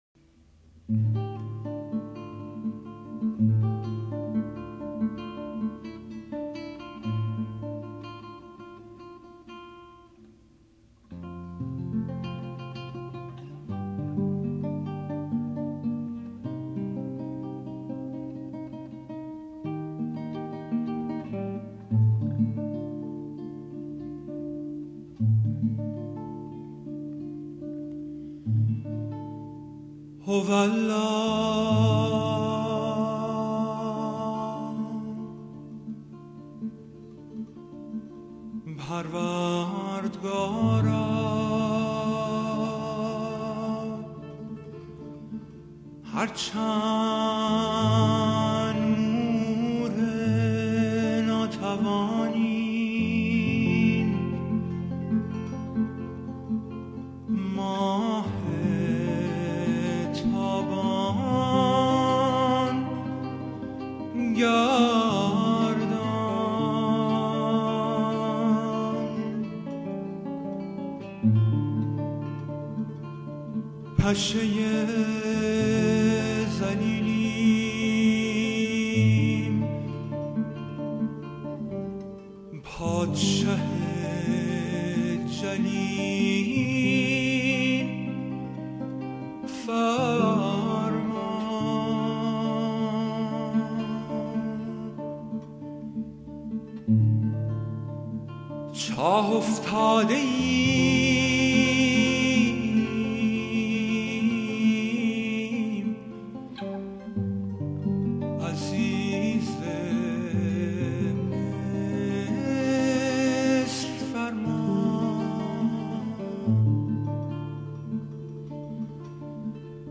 سرود - شماره 7 | تعالیم و عقاید آئین بهائی